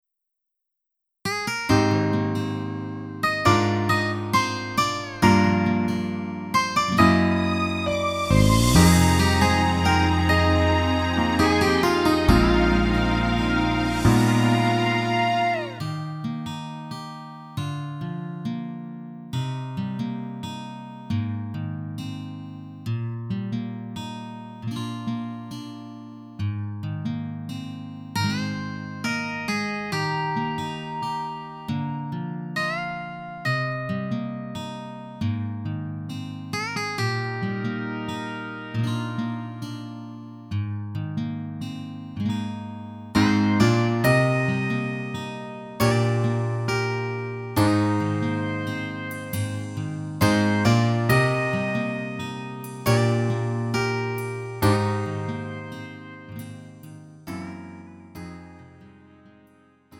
음정 원키 5:36
장르 가요 구분 Lite MR
Lite MR은 저렴한 가격에 간단한 연습이나 취미용으로 활용할 수 있는 가벼운 반주입니다.